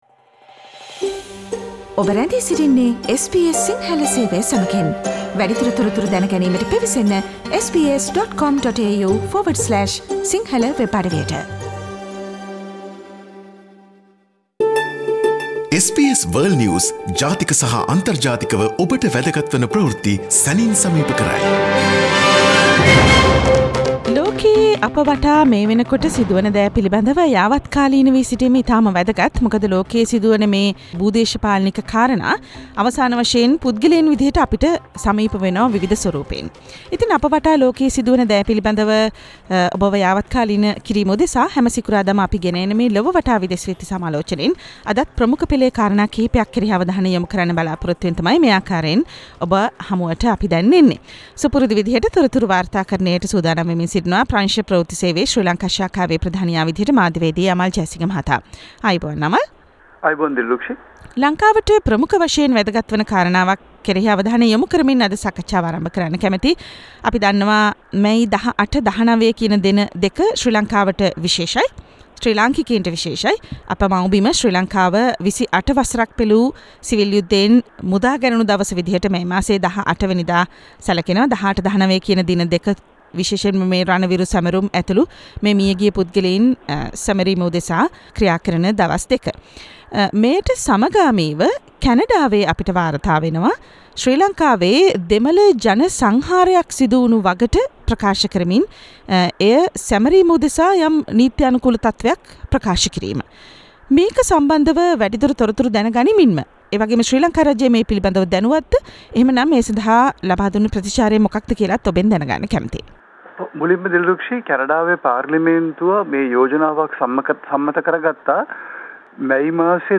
listen to SBS Si Sinhala Radio's weekly world News wrap on Friday Share